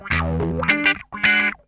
CRUNCHWAH 12.wav